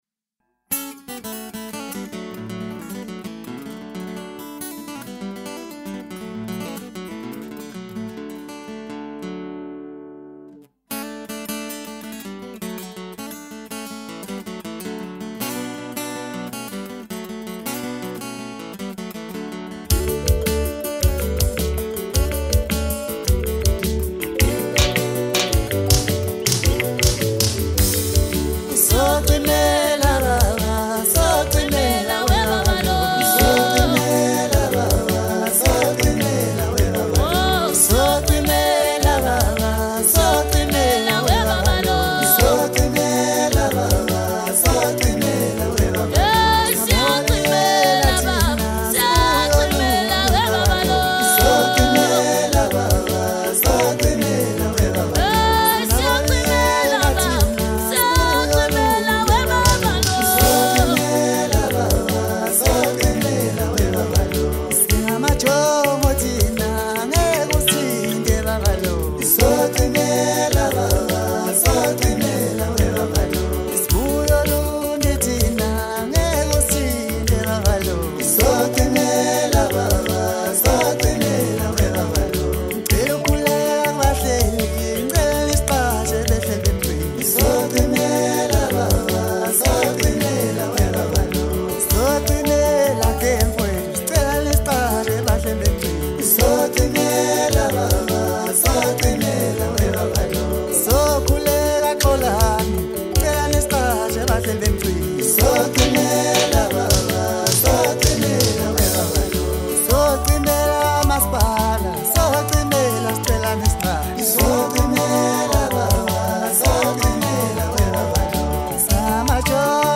Home » Maskandi » Maskandi Music
latest Maskandi Songs and melody